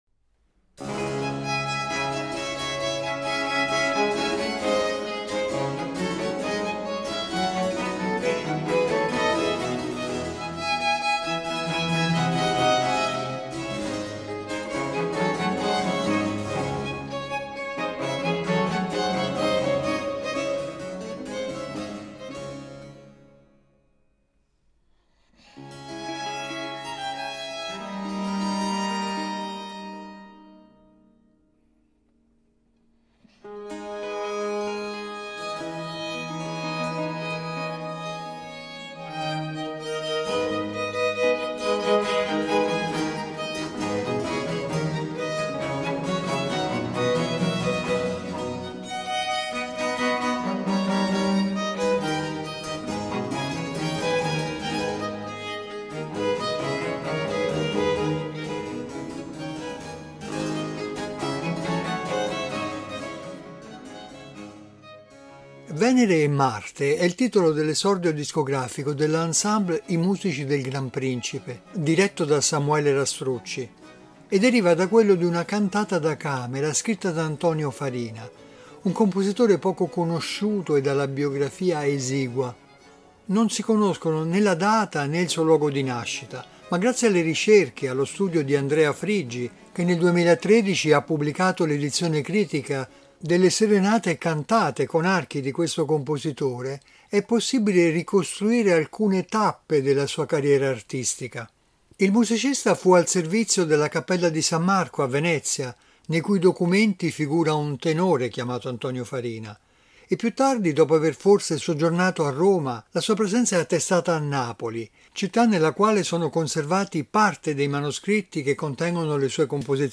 I due eccellenti solisti che intonano le arie dei diversi personaggi di questi dialoghi musicali sono il soprano